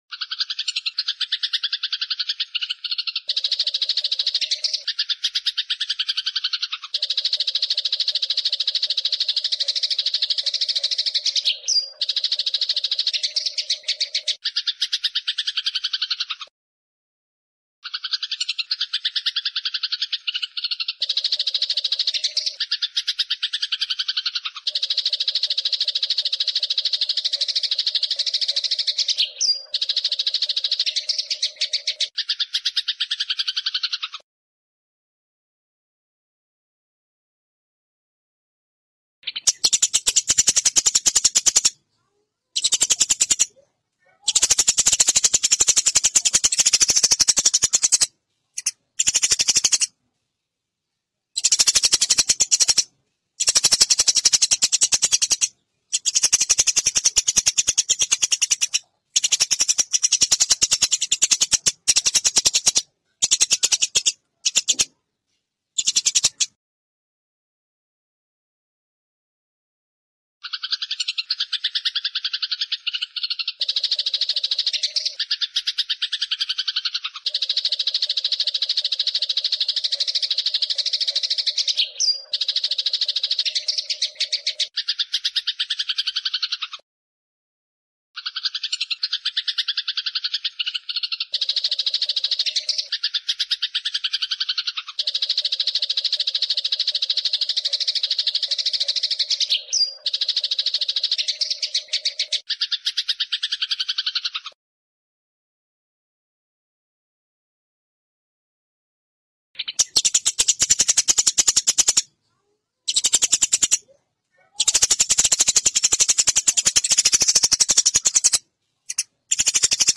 Suara ngeroll, jeda pas, dan kualitas jernih bikin burung makin gacor.
MASTERAN Gereja Tarung Jernih 1 jam
Tag: suara burung Gereja suara masteran burung
suara-masteran-gereja-tarung-jernih-1-jam-id-www_tiengdon_com.mp3